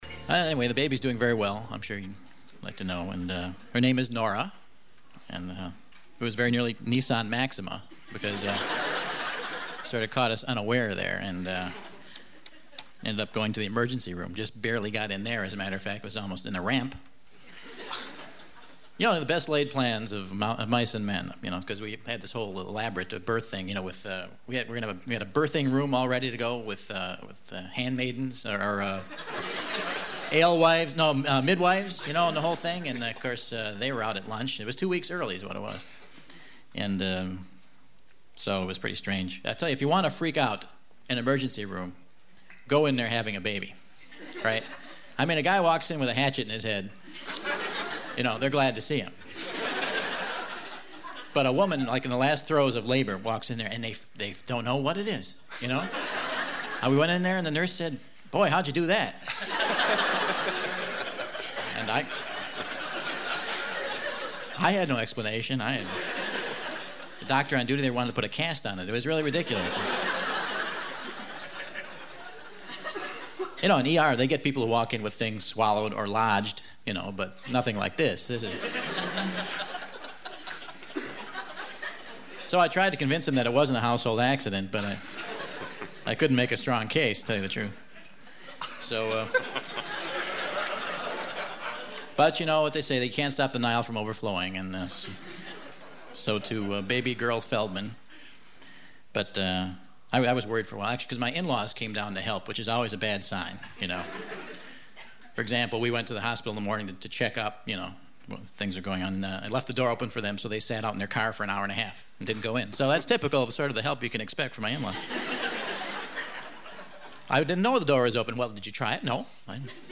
Here is the Whad’ya Know broadcast from November of 1993 to prove it, even if the bouncer at the Kollege Klub won’t accept it–
There were guffaws, then and now.